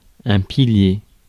Ääntäminen
France (Île-de-France) Paris